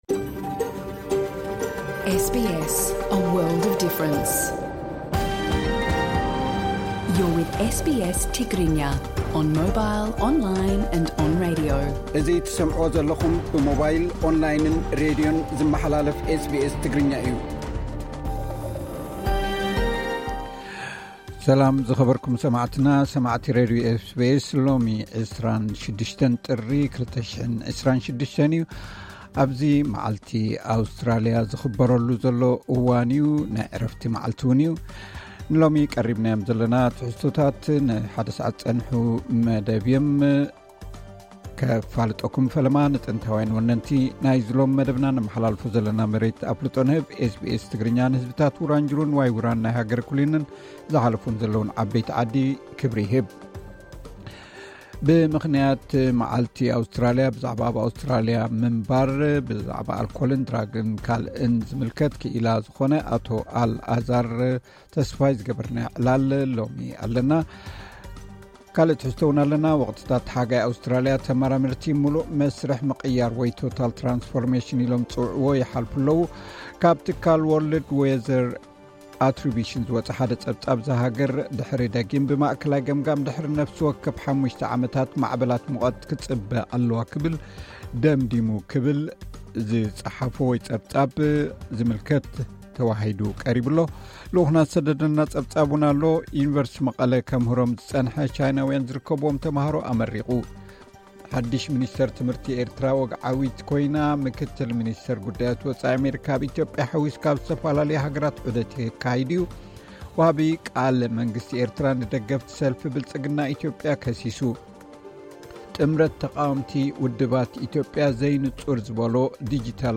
ጥምረት ተቓወምቲ ውድባት ኢትዮጵያ ዘይንጹር ንዝበሎ ዲጂታላዊ ስርዓት ምርጫ ነጺጉ። ኣርእስታት ዜና